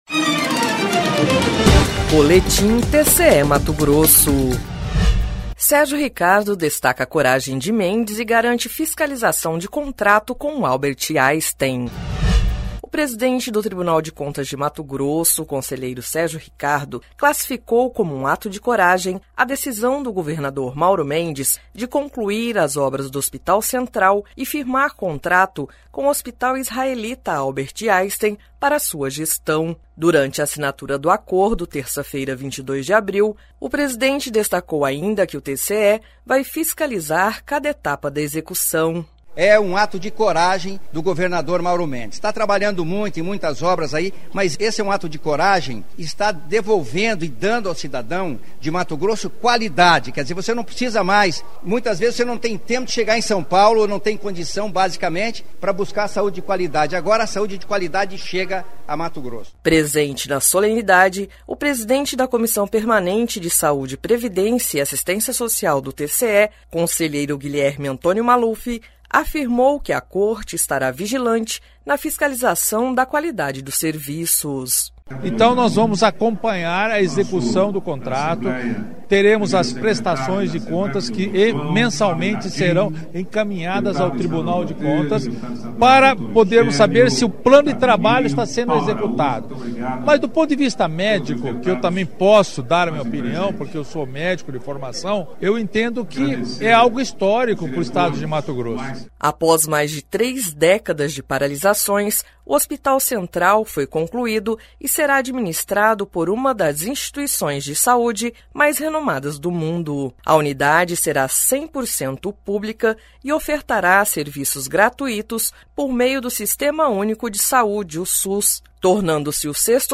Sonora: Sérgio Ricardo – conselheiro-presidente do TCE-MT
Sonora: Guilherme Antonio Maluf – conselheiro presidente da COPSPAS do TCE-MT
Sonora: Mauro Mendes – governador de MT
Sonora: Max Russi – deputado presidente da ALMT